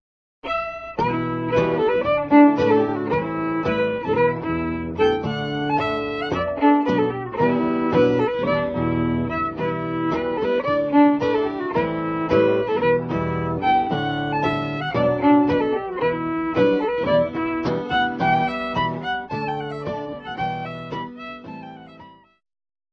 guitarist
an historic Scottish fiddle tune